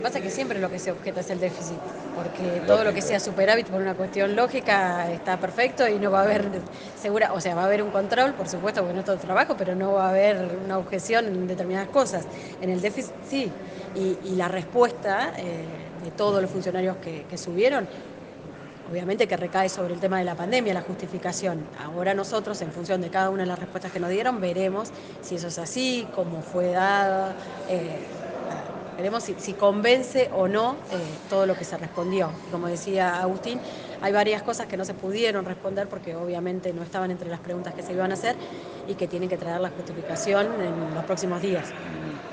En diálogo con Radio 3, los ediles cambiemitas Soledad Cadenas y Agustín Rossi precisaron que primará el pragmatismo a la hora de acompañar o no los números presentados.